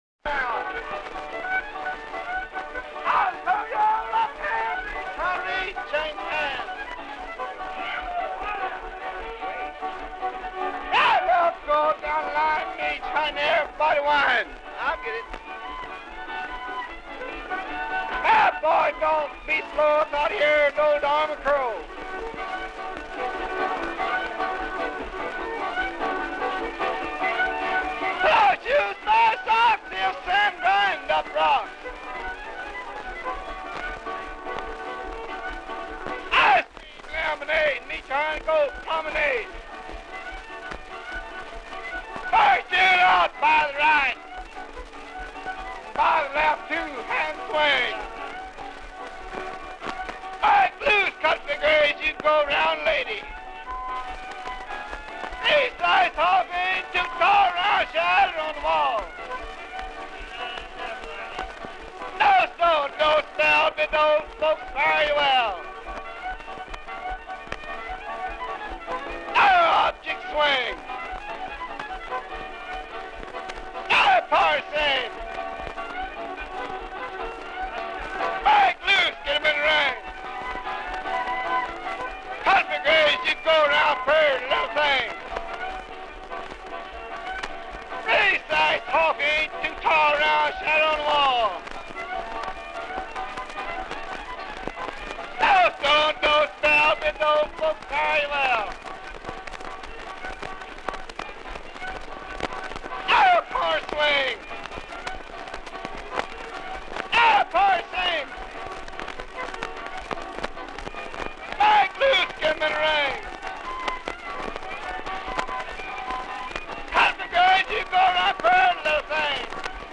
Square dance calling to "Chicken Reel" 1939
square dance calling.